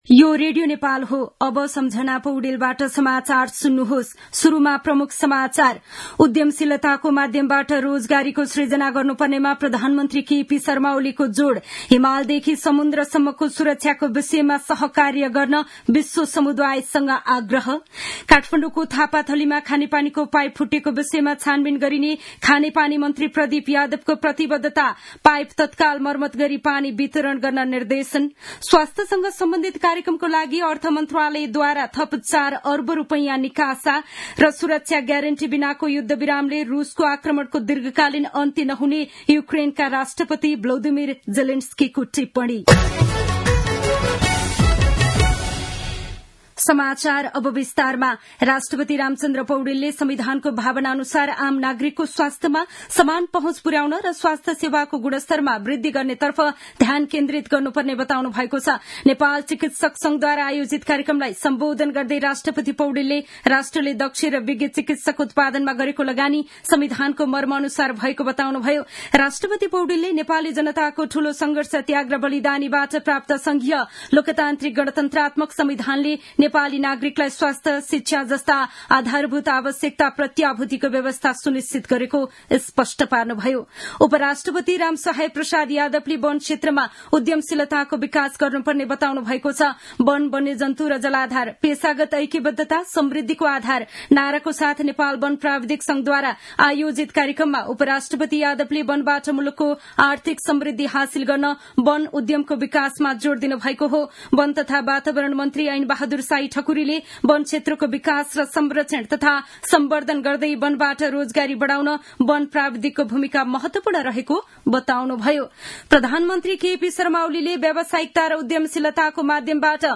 दिउँसो ३ बजेको नेपाली समाचार : २१ फागुन , २०८१
3-pm-news-1-2.mp3